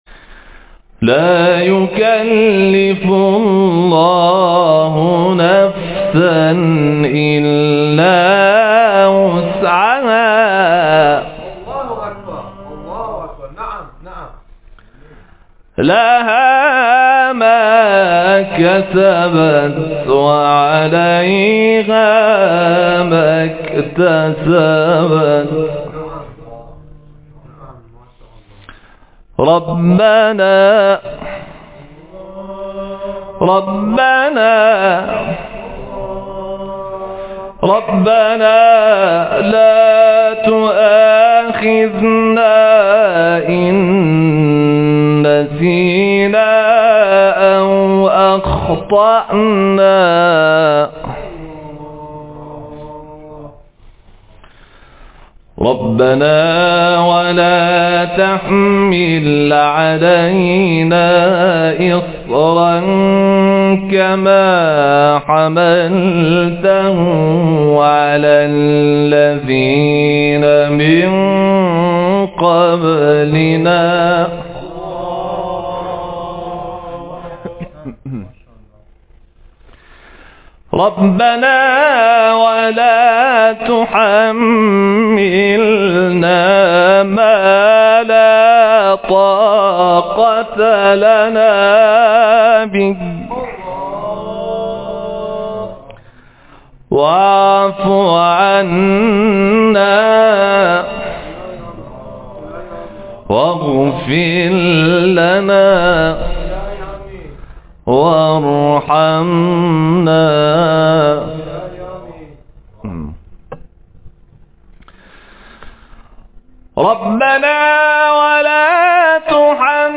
تلاوت حافظ کل و قاری کشورمان از آیه 286 سوره بقره و آیه ابتدایی سوره آل‌عمران منتشر شد.